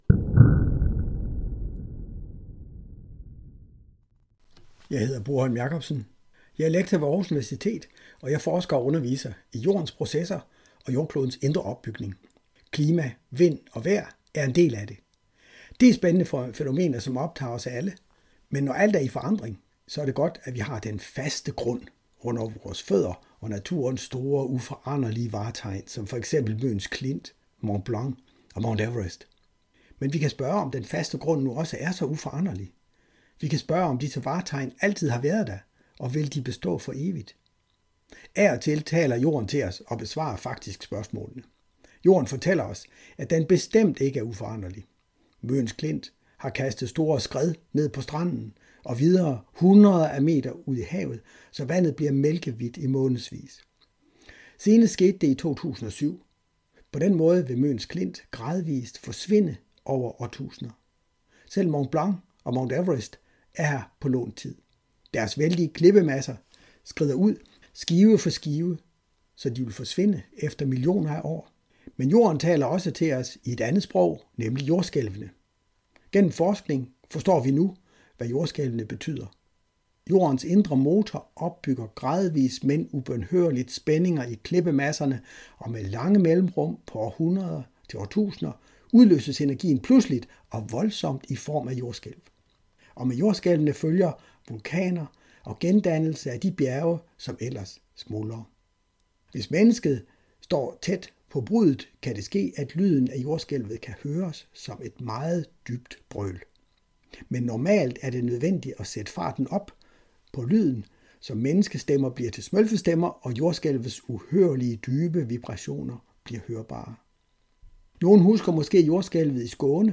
som fylder hele rummet ud og en fortælling i høretlf.